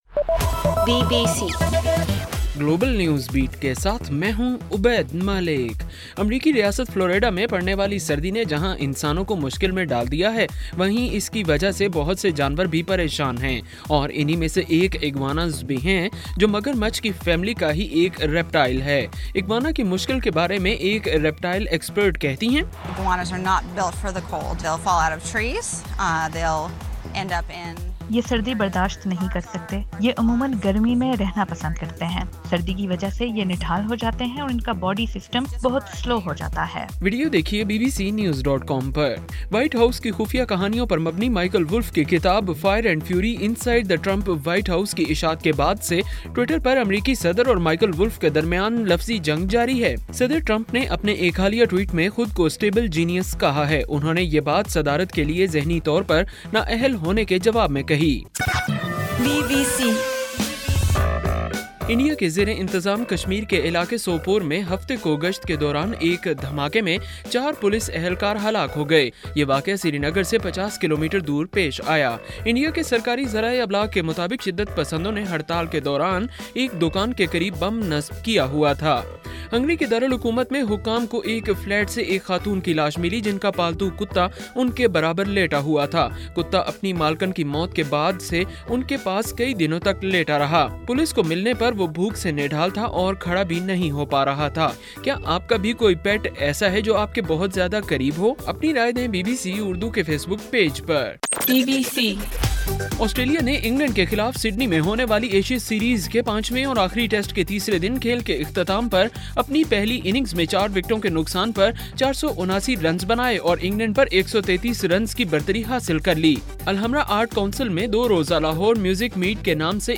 گلوبل نیوز بیٹ بُلیٹن اُردو زبان میں رات 8 بجے سے صبح 1 بجے ہرگھنٹےکے بعد اپنا اور آواز ایف ایم ریڈیو سٹیشن کے علاوہ ٹوئٹر، فیس بُک اور آڈیو بوم پر سنئیِے